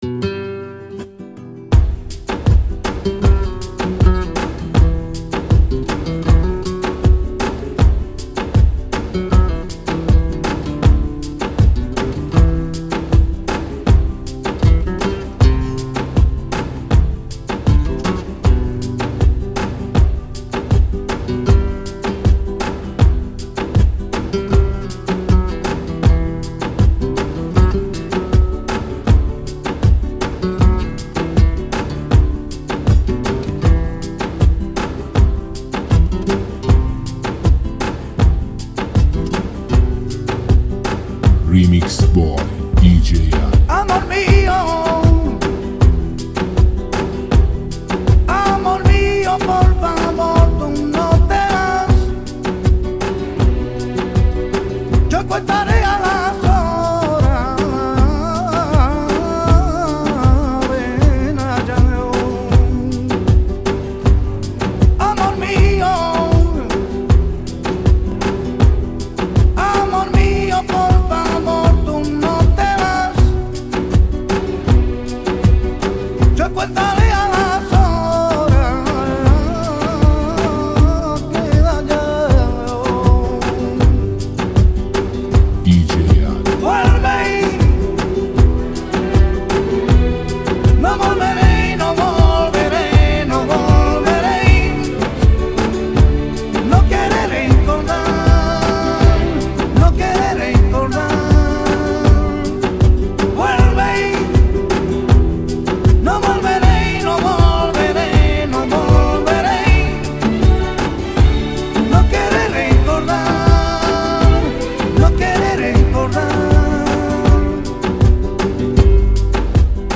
نوای غمگین شکست عشقی
با نوایی سوزناک، بیانگر حس و حال جدایی و تنهایی است.